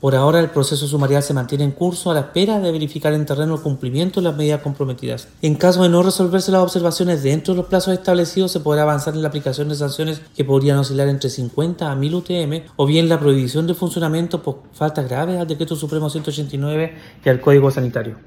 Hasta entonces, el proceso sumarial continúa abierto y, de no acreditarse el cumplimiento de las medidas, se arriesgan sanciones. Tal como lo confirmó el Seremi de Salud regional Andrés Cuyul.